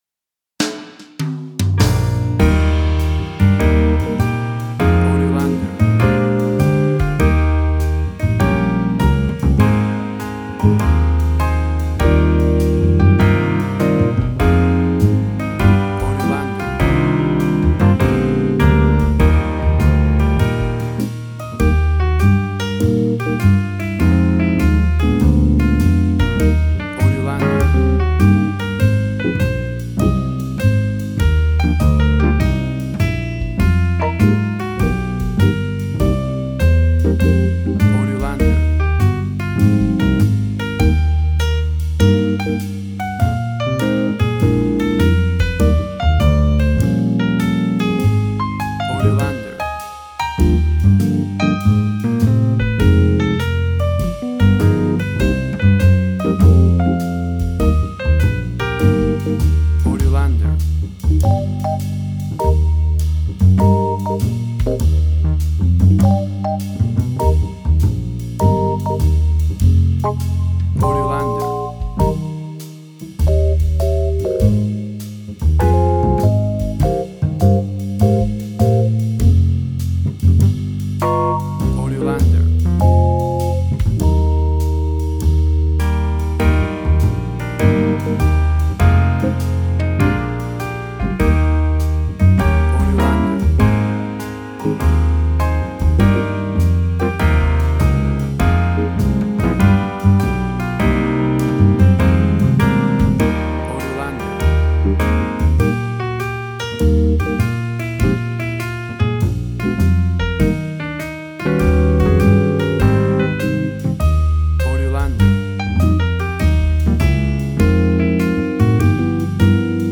WAV Sample Rate: 16-Bit stereo, 44.1 kHz
Tempo (BPM): 100